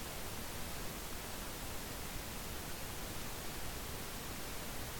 I get a lot of noise when recording audio via a microphone in headset plugged into the 2.5mm jack.
) was done with nothing plugged into the 2.5mm jack at all.
In both cases the gain is set to 45% in pavucontrol.